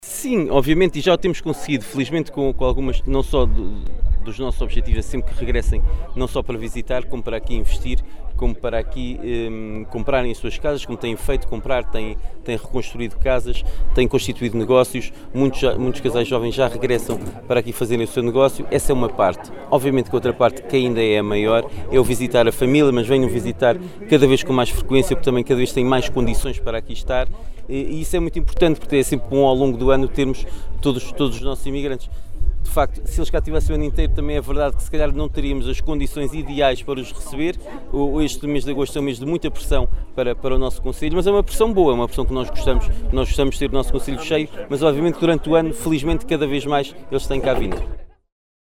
O Edil “Paivense”, disse que, ultimamente se tem verificado o regresso de emigrantes, nomeadamente, casais jovens, que estão a investir no concelho, com a criação do seu próprio negócio.